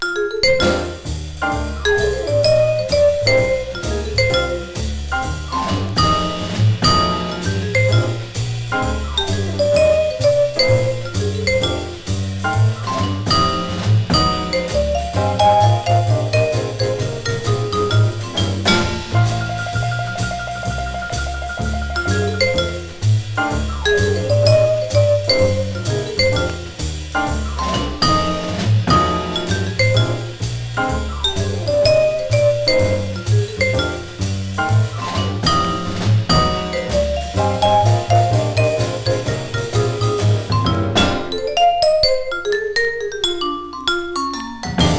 Jazz　USA　10inchレコード　33rpm　Mono